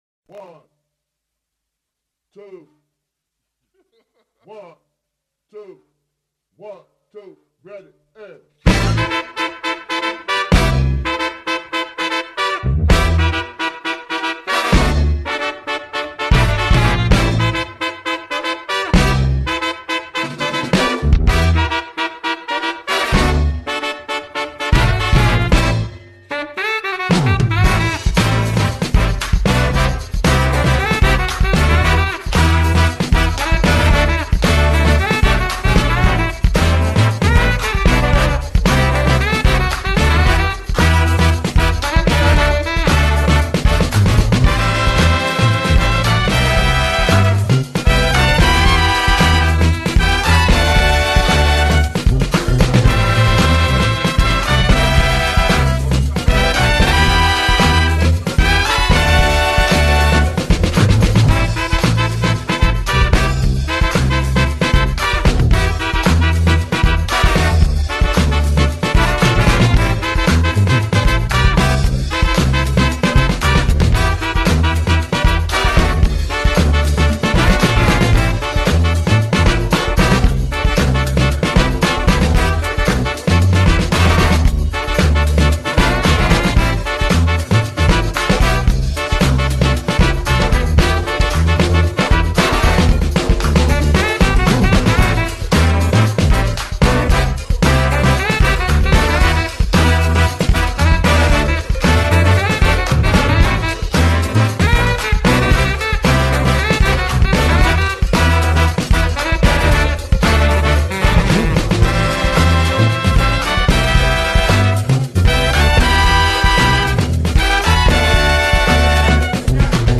Brass Brand Remix